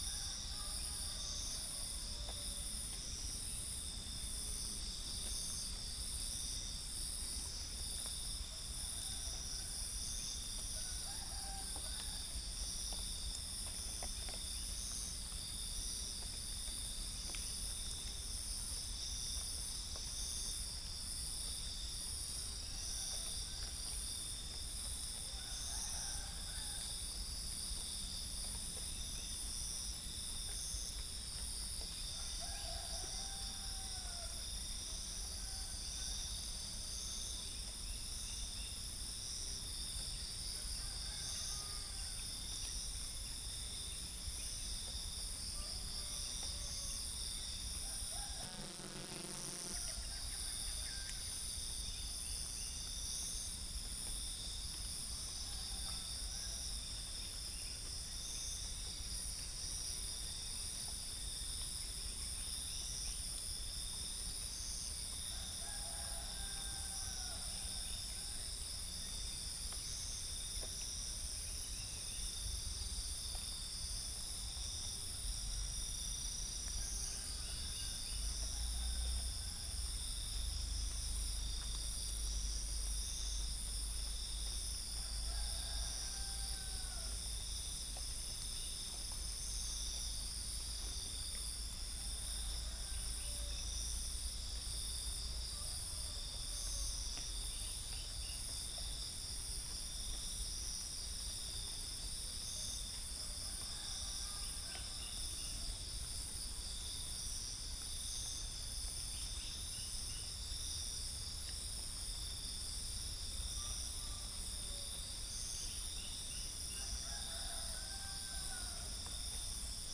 Upland plots dry season 2013
Centropus sinensis
Orthotomus sericeus
Pycnonotus goiavier
Gallus gallus domesticus